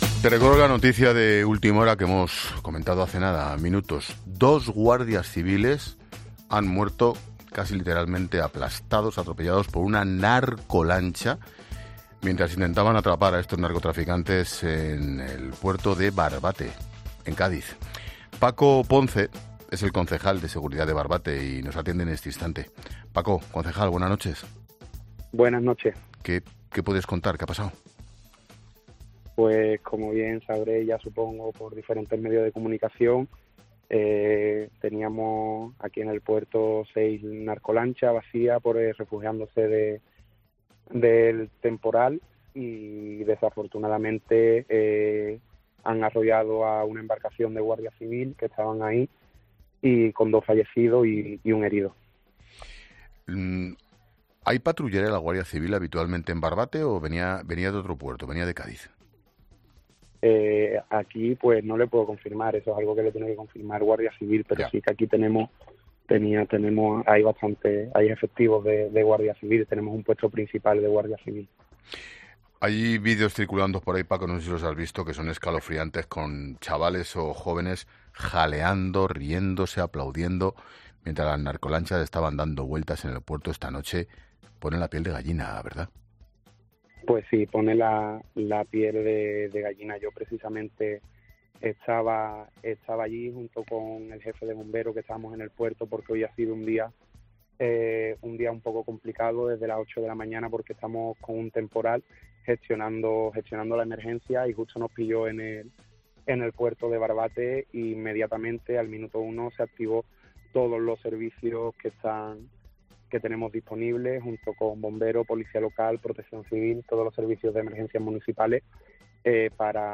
Escucha la entrevista de Expósito al concejal de Barbate testigo de la muerte de 2 guardias civiles